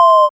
1706R SYNTON.wav